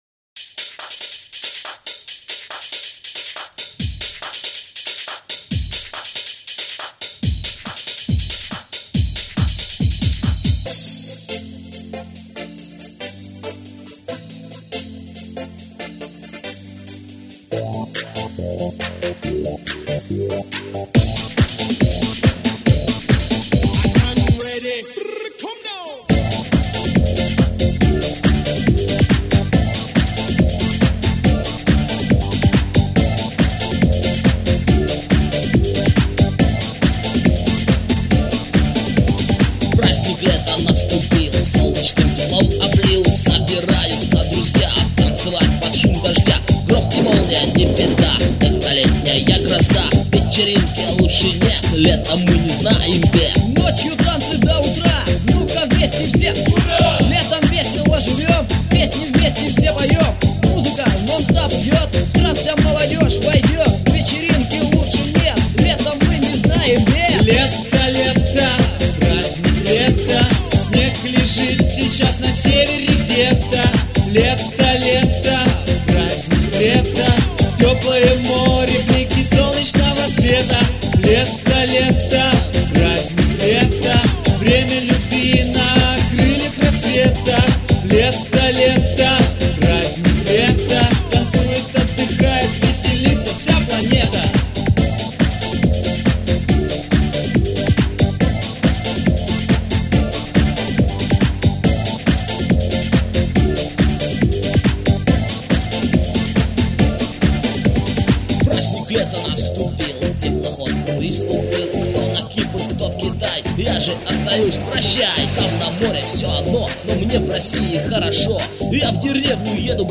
А вот у меня тоже есть свой вариант летнего Евродэнса, правда это скорее в творчество, но сюда как-то по теме
ни чего так, стебно, весело!
Нет вокал, не мне, первая половина рэпов мной исполнена и текст полностью мной написан! smile.gif